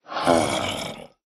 僵尸村民：低吼
Minecraft_zombie_villager_say2.mp3